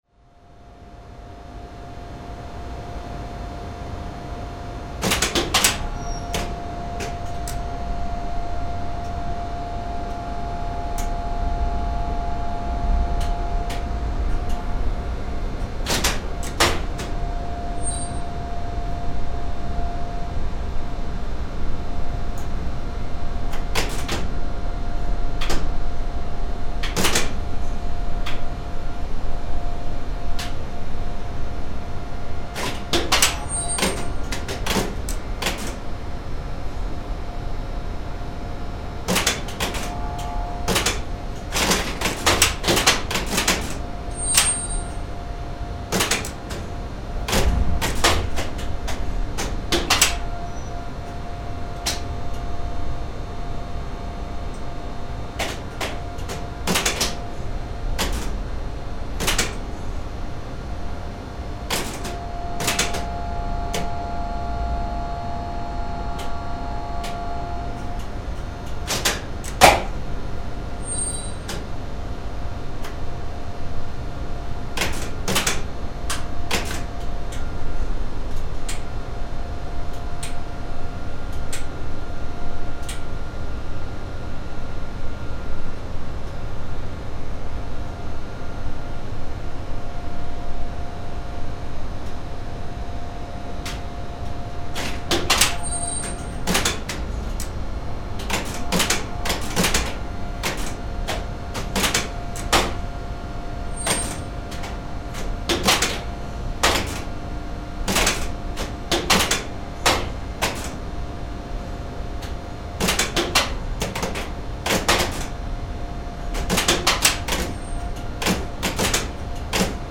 Upptakan sem hér er að finna var gerð í gámi inni í vélahúsi „Jakans“. Þar er allur stýribúnaður hans sem byggist á mörgum spólurofum og stórum DC-rofum (rakstraumsrofum). Hljóðdæmið lýsir ágætlega ótal rofasmellum sem fylgja því að færa einn gám frá vagni yfir í skip og aftur í land. Tekið var upp í 44.1 kHz/16 bit með Rode NT2A hljóðnemum.